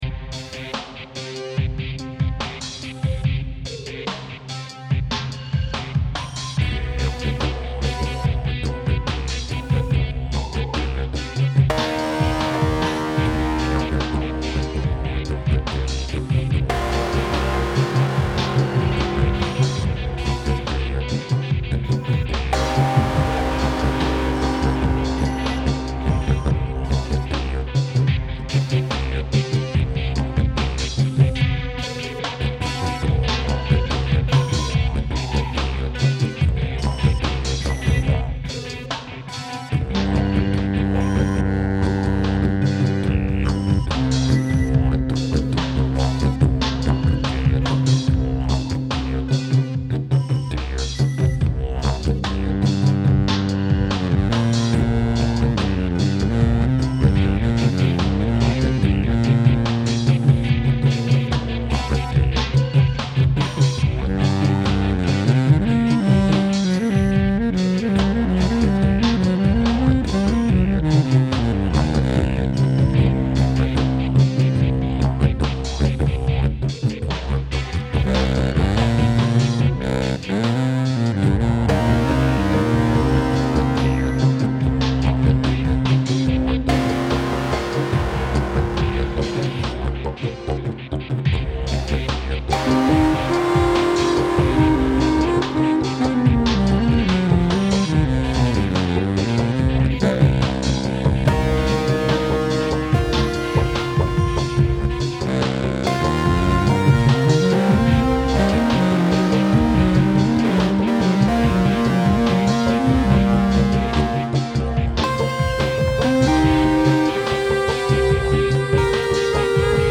Nun mit Sopranino- und Bariton-Saxofon!